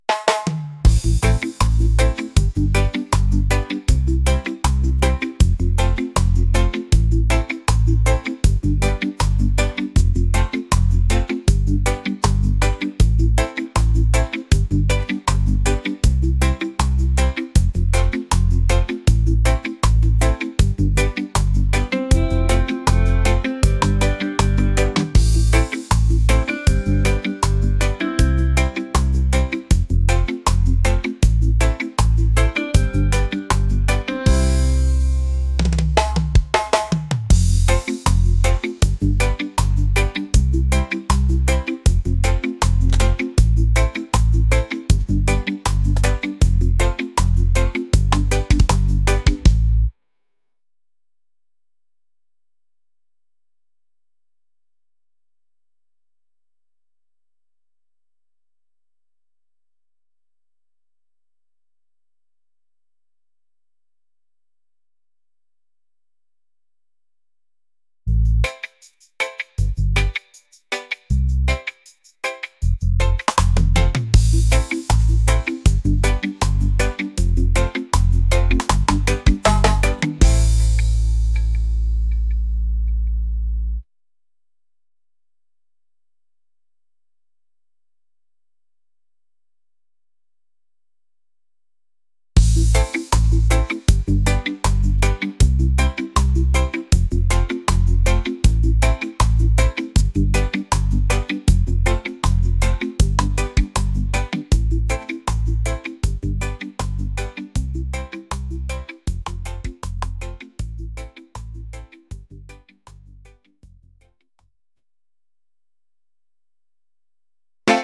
island | laid-back | reggae